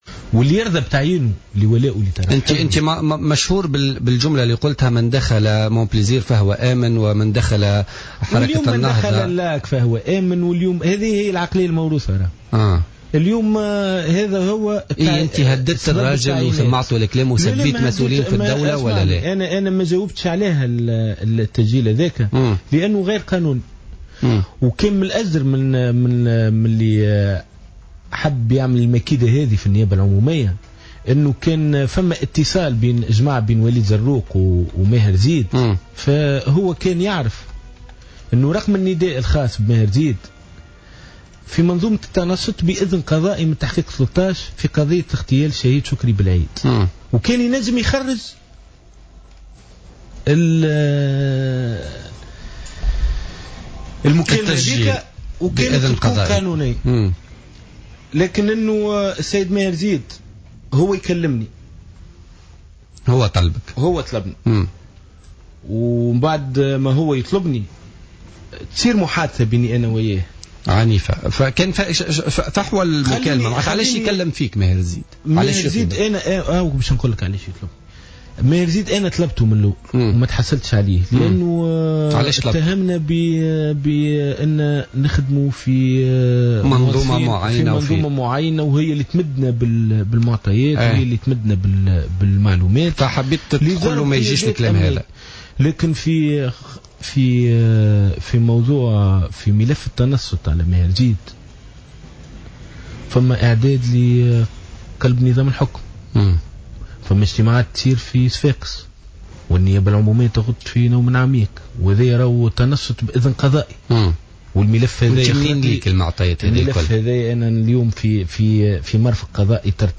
مقابلة